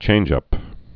(chānjŭp)